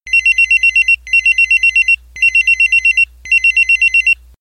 Default New Call